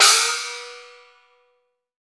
Index of /90_sSampleCDs/300 Drum Machines/Akai MPC-500/3. Perc/ChinesePrc